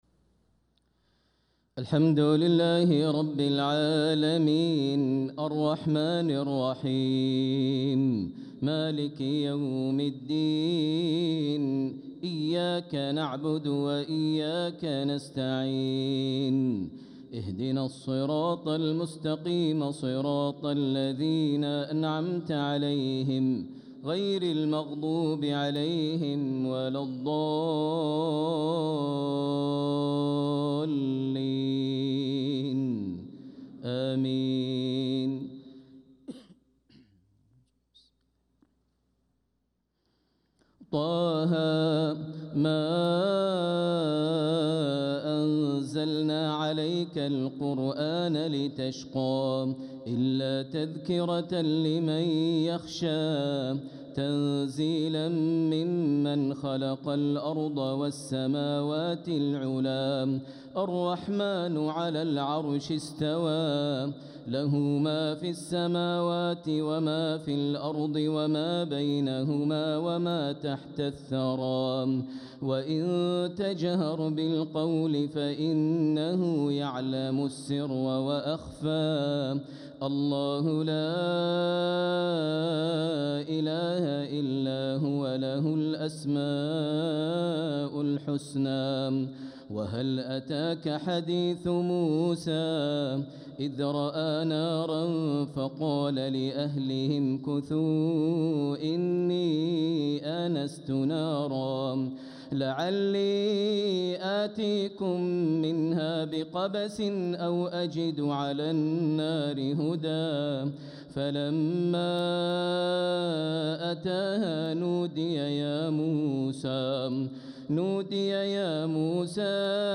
صلاة العشاء للقارئ ماهر المعيقلي 11 ربيع الآخر 1446 هـ
تِلَاوَات الْحَرَمَيْن .